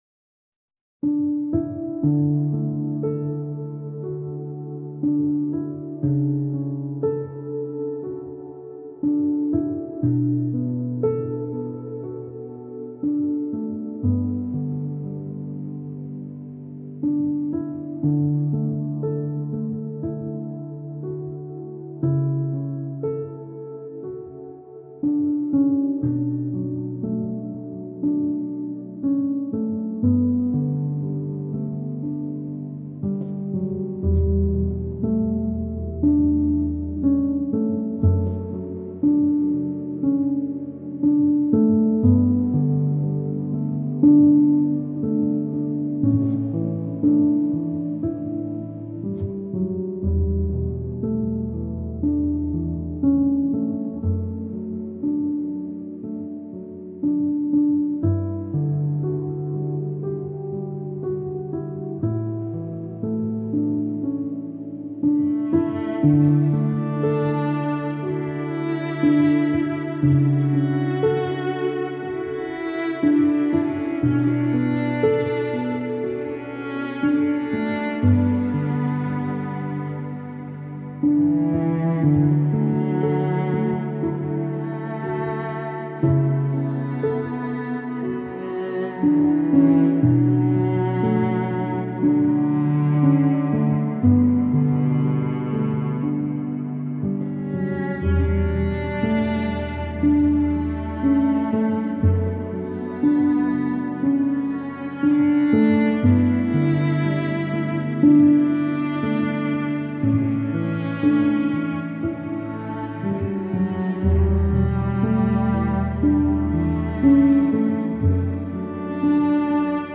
آرامش بخش
پیانو